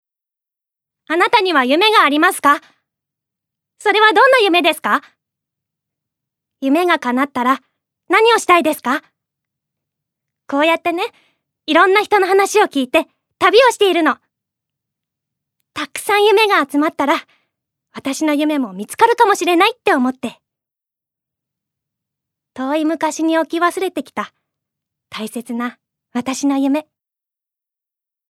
ボイスサンプル
台詞1